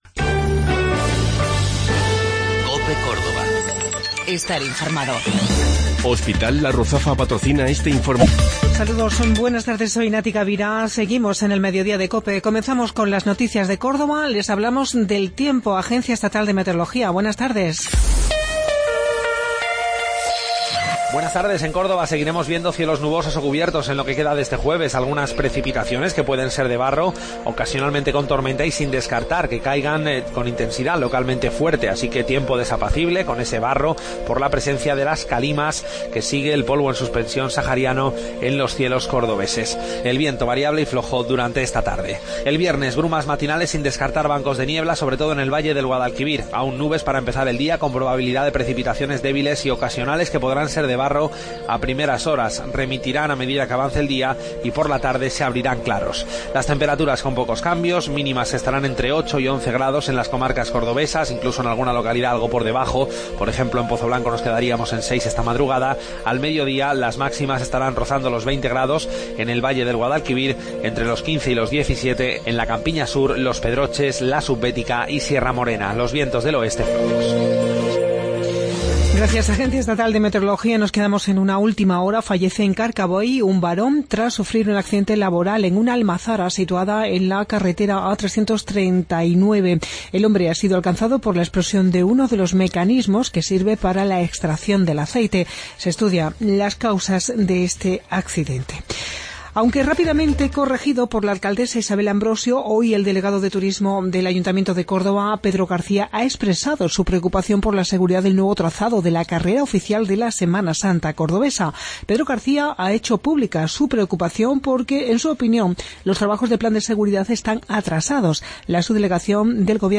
Mediodía en Cope. Informativo local 23 de Febrero 2017